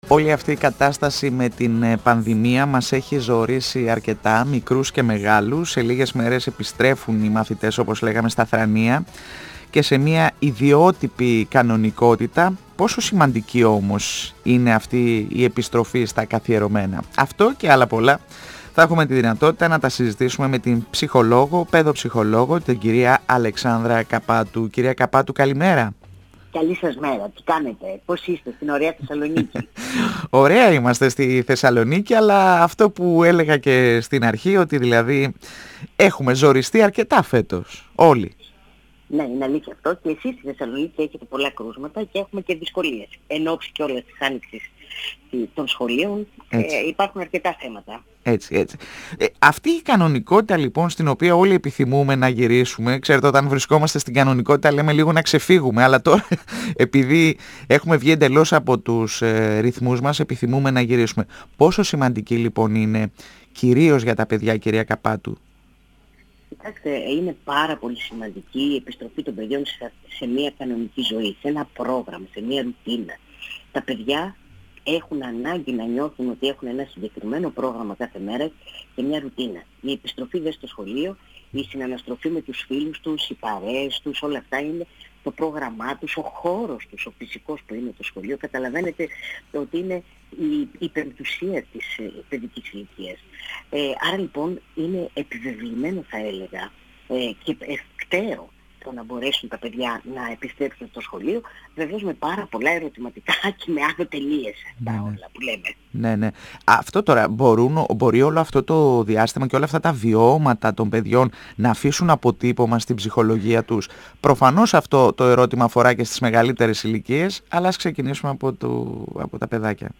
μιλώντας στον 102 fm της ΕΡΤ3. Παράλληλα, αναφέρθηκε στην ατομική ευθύνη λέγοντας πως αυτή συνδέεται με την παιδεία και το μορφωτικό επίπεδο.